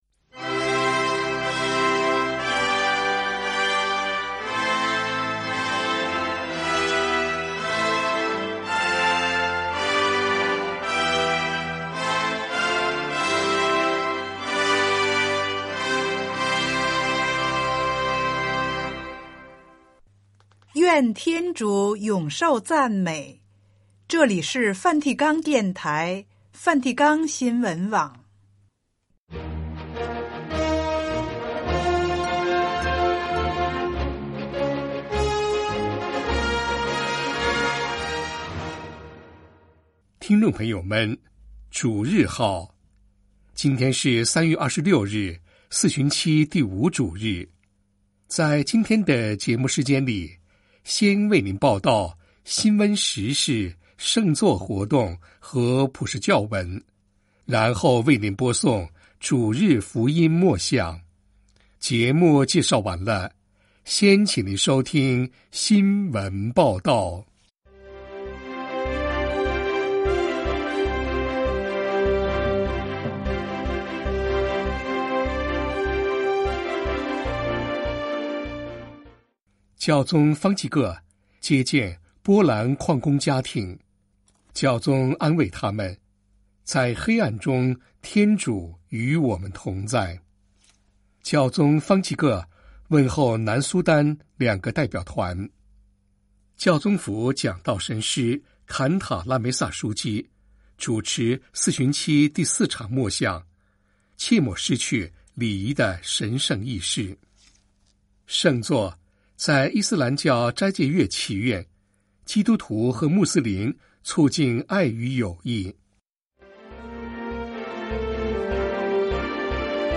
有干扰，听不完整断句，不知道是哪里的信号不好还是其他原因